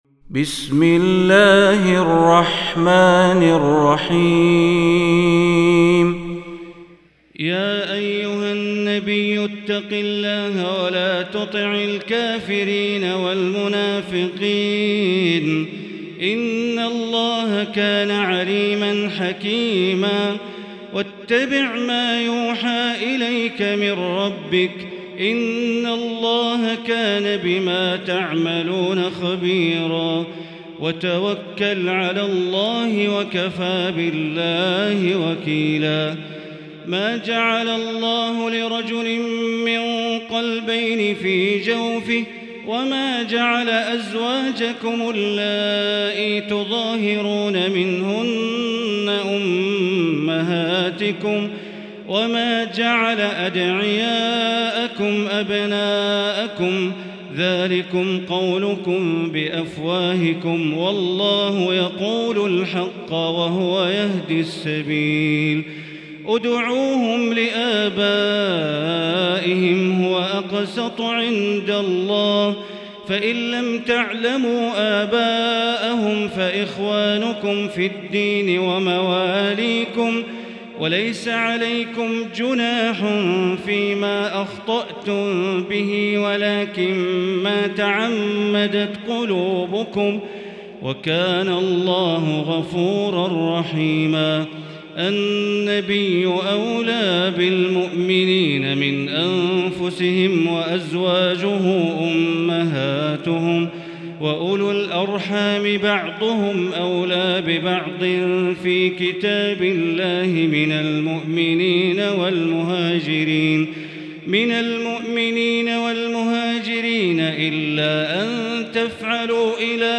المكان: المسجد الحرام الشيخ: معالي الشيخ أ.د. بندر بليلة معالي الشيخ أ.د. بندر بليلة الأحزاب